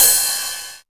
RIDE2     -L.wav